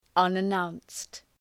Προφορά
{,ʌnə’naʋnst}